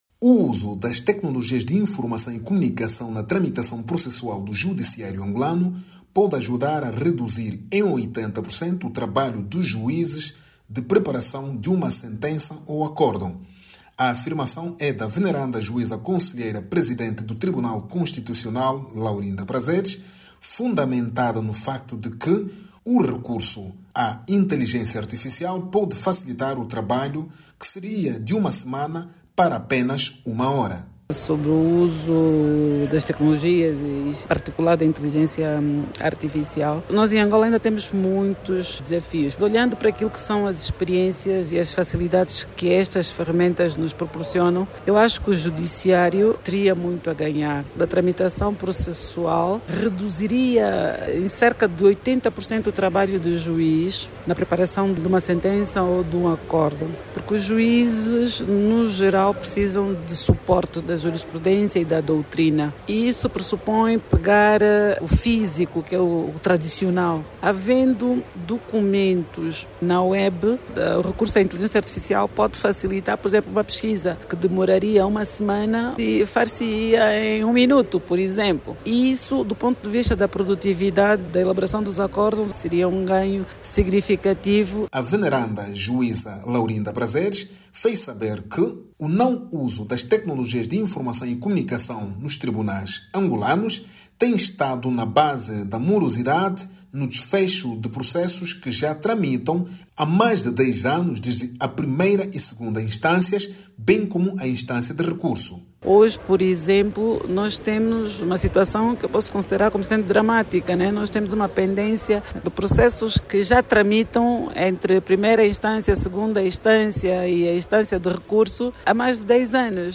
A magistrada falava, em exclusivo à RNA, a partir de Joanesburgo, na África do Sul, onde participa, como convidada, na Cimeira dos Presidentes dos Tribunais Constitucionais e Supremos dos países do G20.